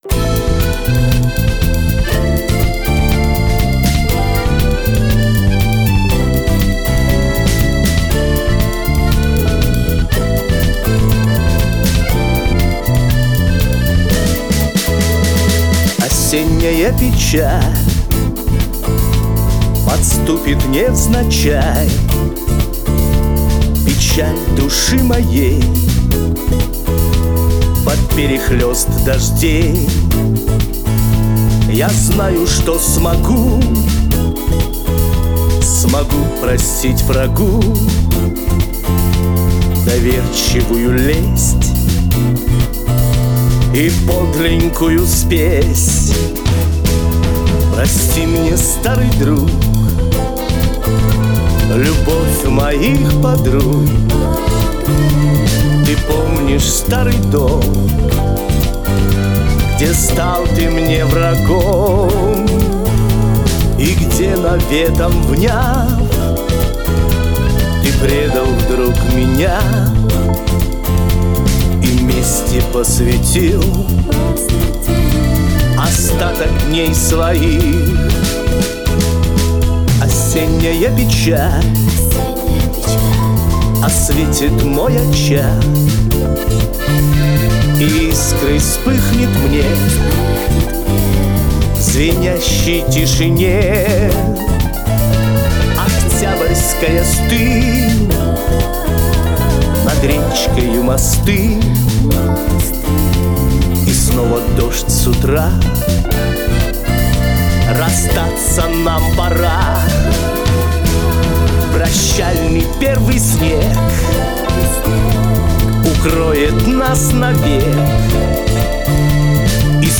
Красиво и ... грустно!